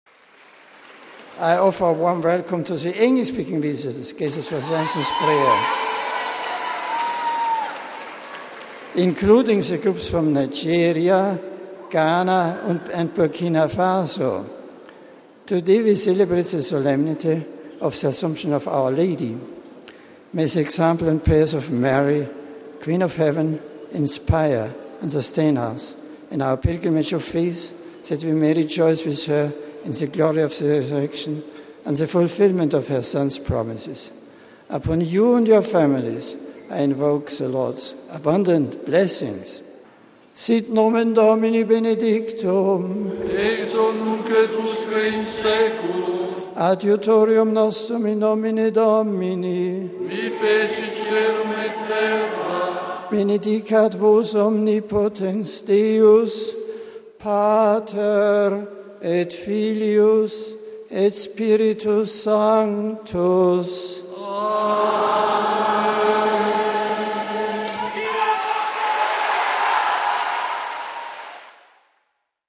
The Pope shared his reflection with pilgrims and visitors who joined him at noon to pray the ‘Angelus’ at the papal summer residence of Castel Gandolfo, southeast of Rome, on August 15 that is a holiday in the Vatican and in Italy.
Pope Benedict XVI also greeted those present at the ‘Angelus’ prayer in several languages, including in English.